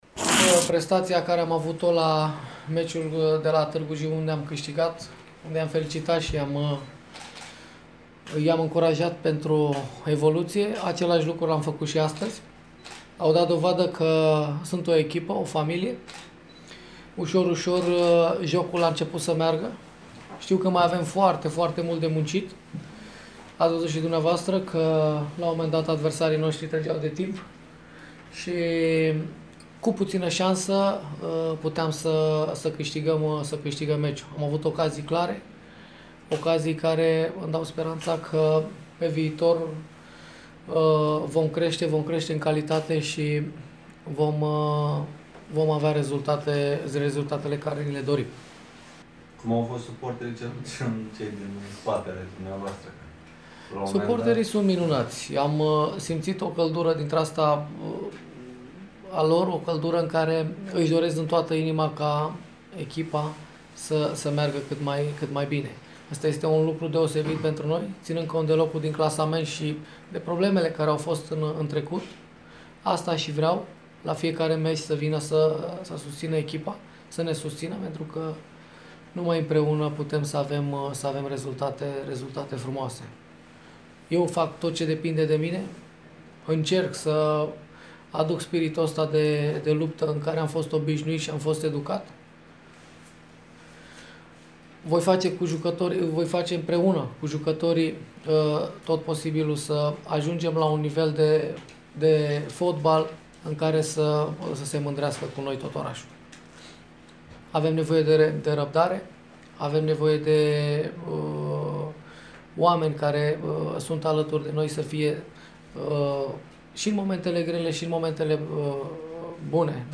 Imediat după meci, antrenorul sucevenilor, Florentin Petre, a susţinut o conferinţă de presă a cărei înregistrate o puteţi asculta mai jos: